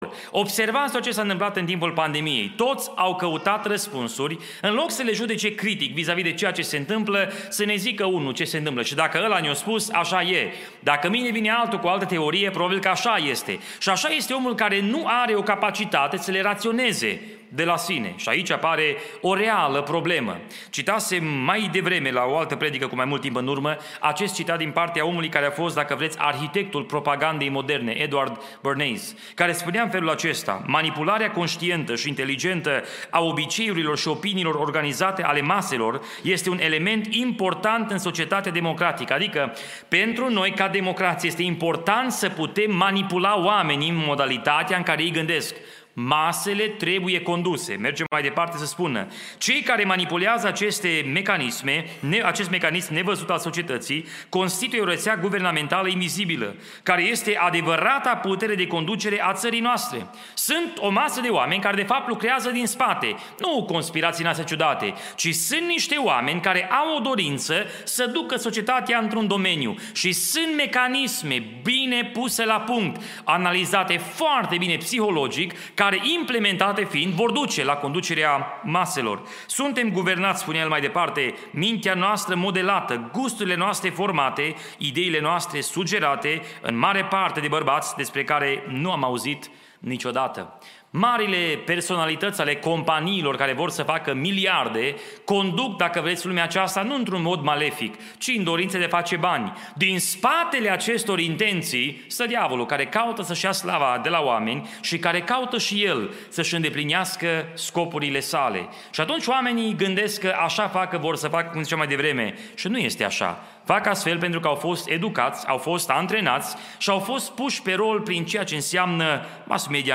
Cuprinde o selectie de predici audio si text care te ajuta sa intelegi de unde vii, cine esti si ce vrea Dumnezeu de la tine.